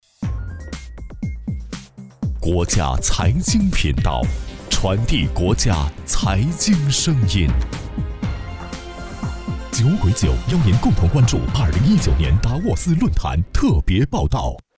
语言：普通话 （155男）
特点：大气浑厚 稳重磁性 激情力度 成熟厚重
央视财经频道台呼.mp3